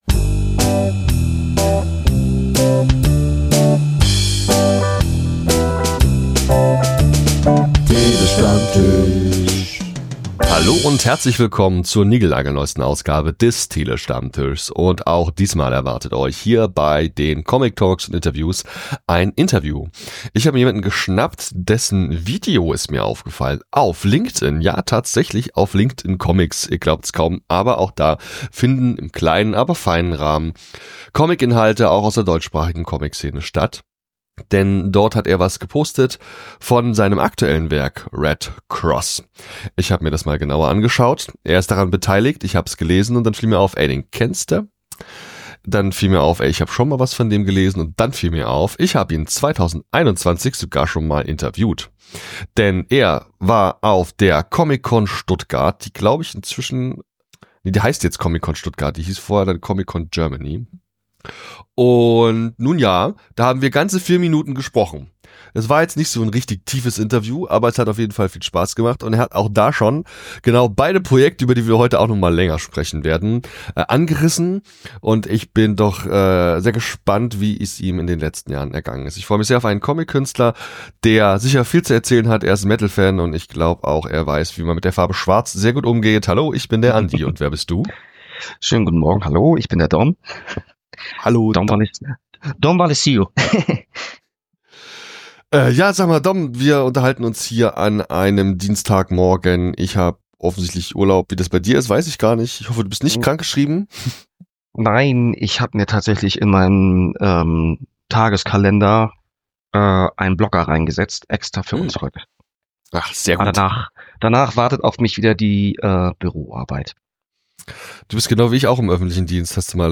Der Tele-Stammtisch - Comictalks & Interviews » TST107